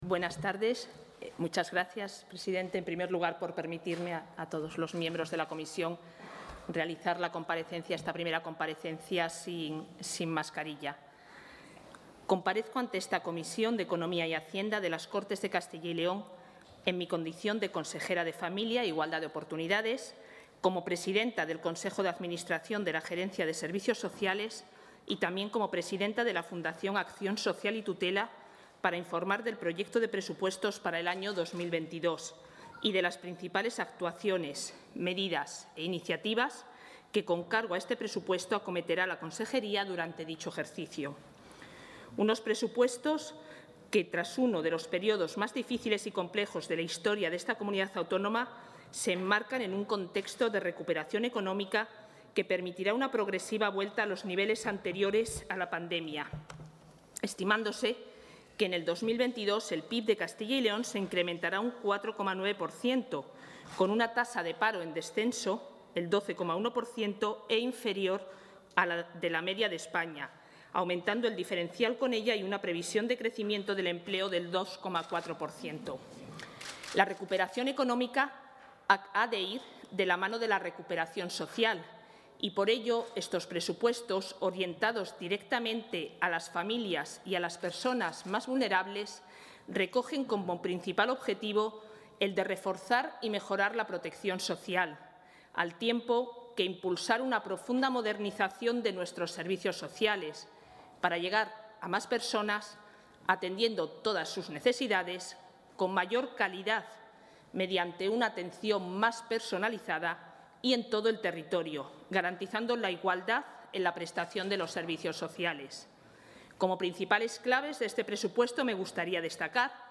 Intervención consejera.
La consejera de Familia e Igualdad de Oportunidades, Isabel Blanco, ha comparecido esta tarde ante la Comisión de Economía y Hacienda de las Cortes de Castilla y León para detallar las principales partidas, programas e inversiones consignadas por su departamento en el Proyecto de Ley de Presupuestos Generales de la Comunidad para 2022.